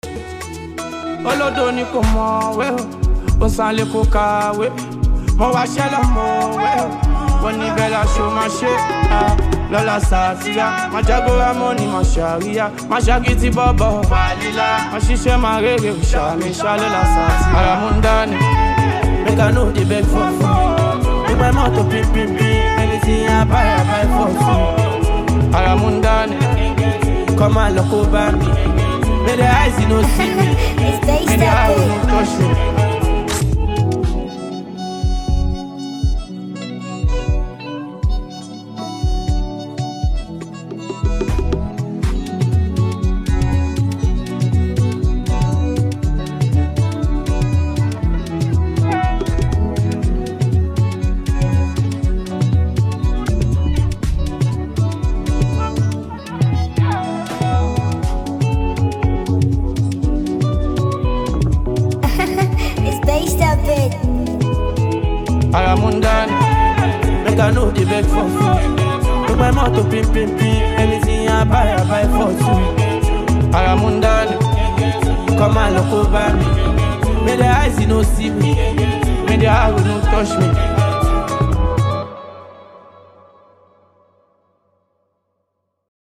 Download instrumental mp3 below…